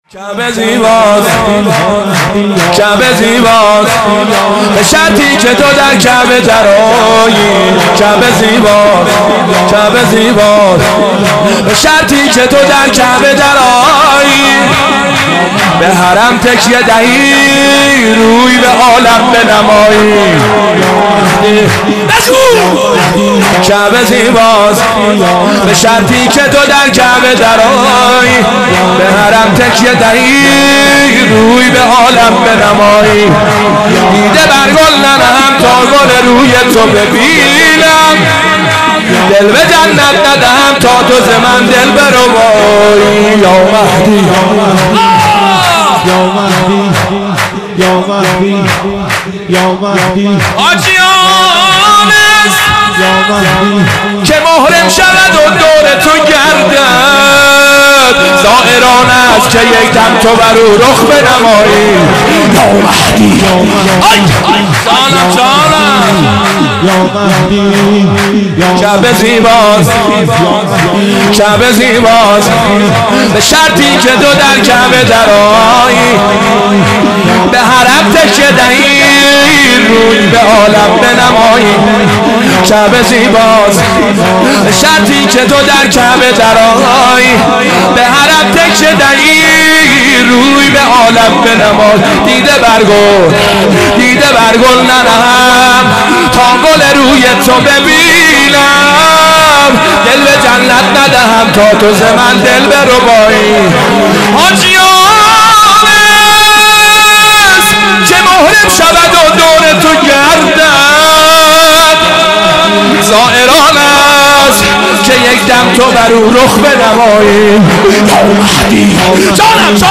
مناسبت : ولادت حضرت مهدی عج‌الله تعالی‌فرج‌الشریف
قالب : شور